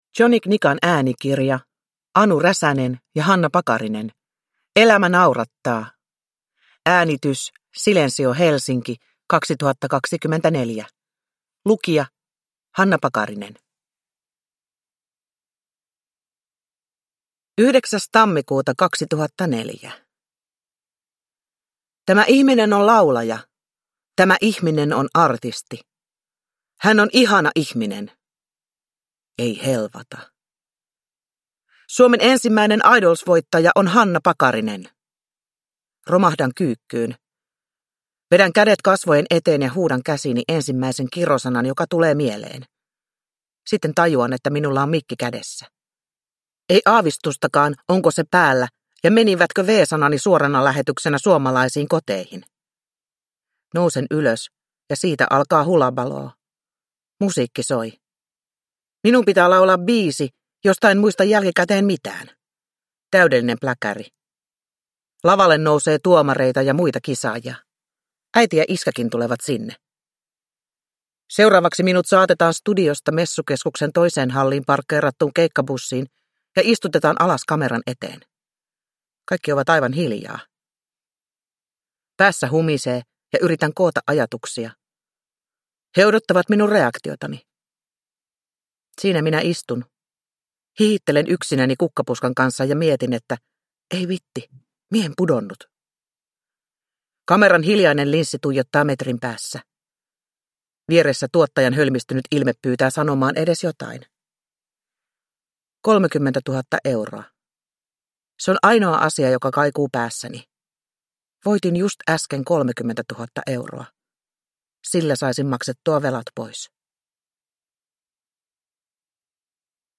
Hanna Pakarinen – Elämä naurattaa – Ljudbok
Uppläsare: Hanna Pakarinen